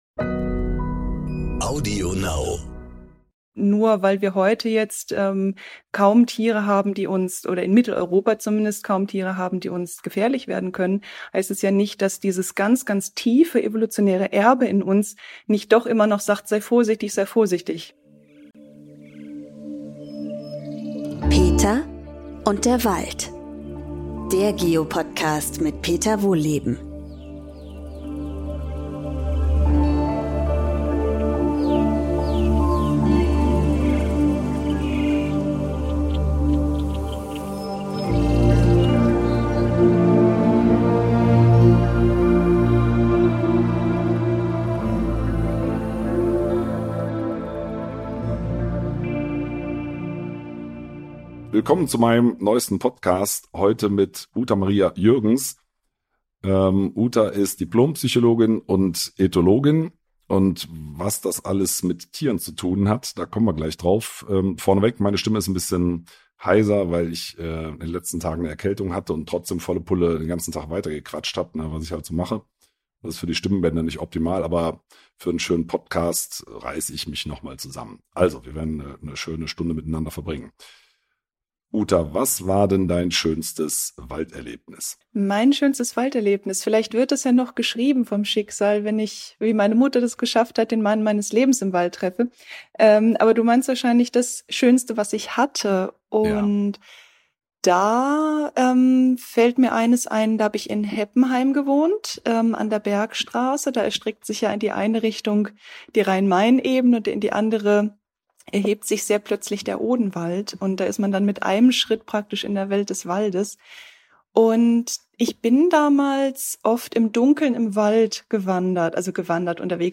Gehören wir zur Natur oder nicht? Um diese zentralen Fragen dreht sich diesmal alles im Gespräch zwischen Peter Wohlleben